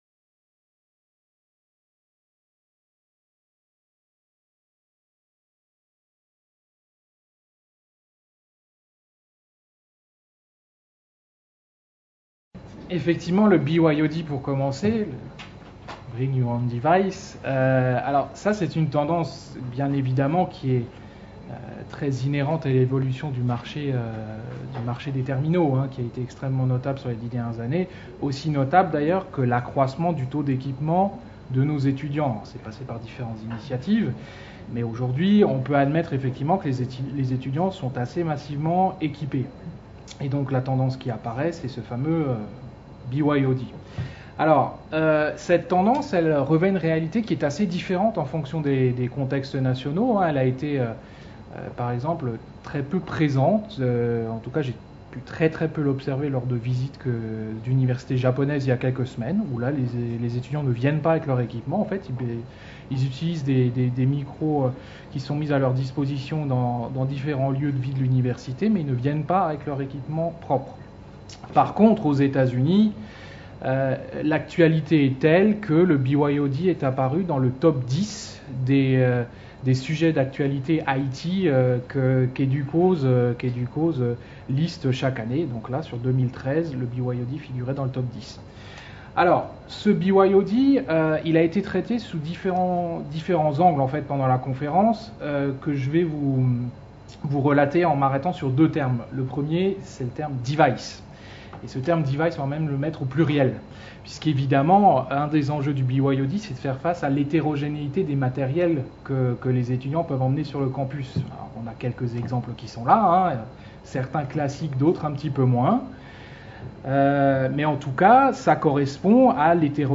Restitution de la mission à EDUCAUSE organisée par l’AMUE le 8 novembre 2013 à la maison des universités. Les participants de la délégation française partagent les principaux sujets abordés à EDUCAUSE 2013 et les bonnes pratiques identifiées à l’occasion de pré-visites aux universités américaines.